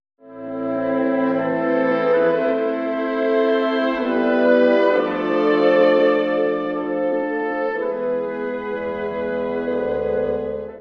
（序奏）
↑古い録音のため聴きづらいかもしれません！（以下同様）
スコットランド旅行で得た、雄大な序奏から始まります。